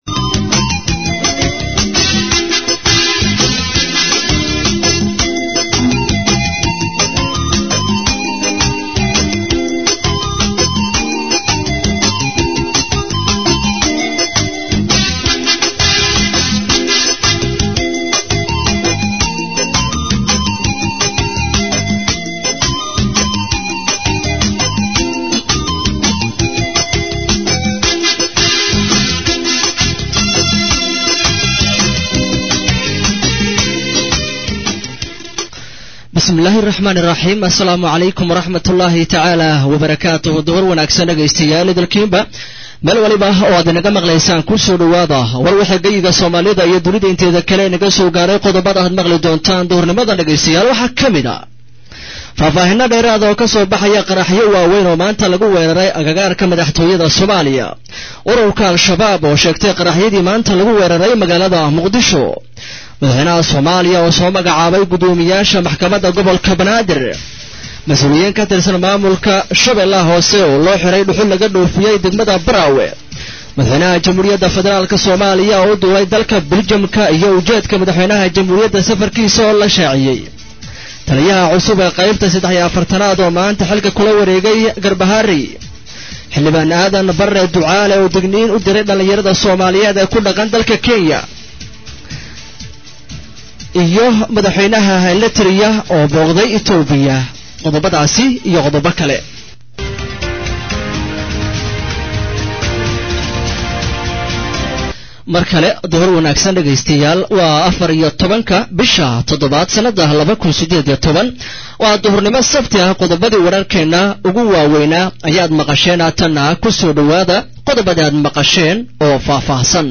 Warka duhur waxaa soo jeedinaya